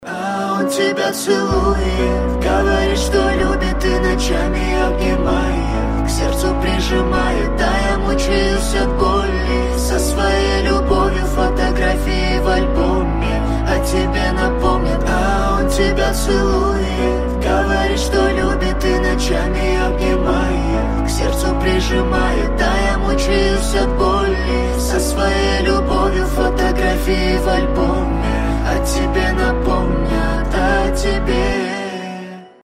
• Качество: 320, Stereo
грустные
дуэт
медлячок
кавер